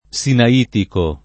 Sina&tiko] etn. (del Sinai); pl. m. -ci — es.: la regione sinaitica; le iscrizioni sinaitiche; il codice sinaitico (lat. codex Sinaiticus [k0dekS Sina&tikuS]), il più antico manoscritto della Bibbia in greco, trovato nel Sinai